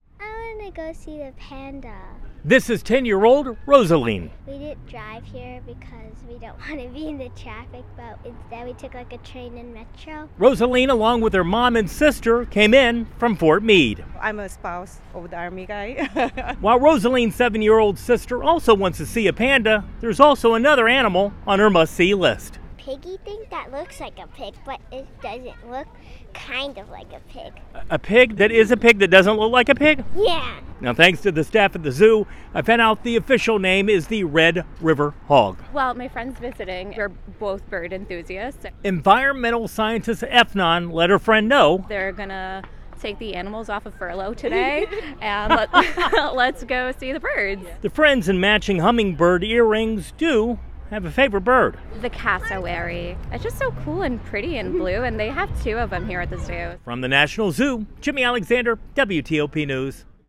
WTOP spoke to some visitors, including two friends who are both bird enthusiasts.